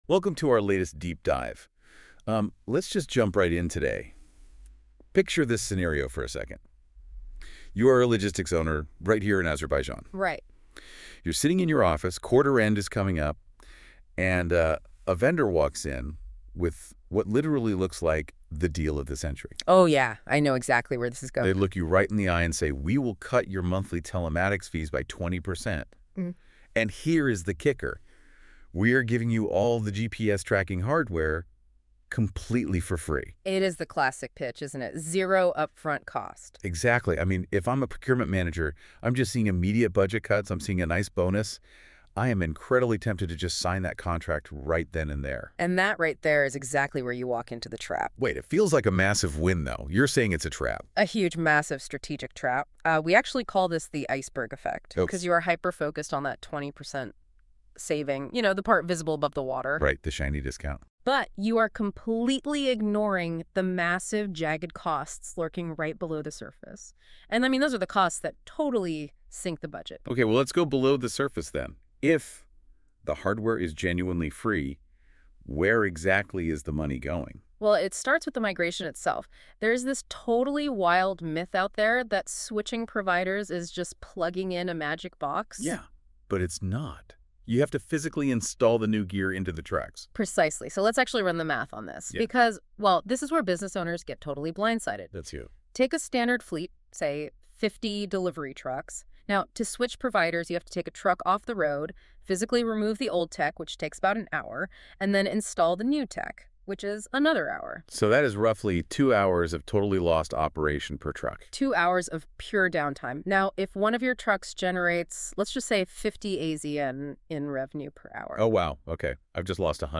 Press play below to hear the AI-generated podcast version of this article.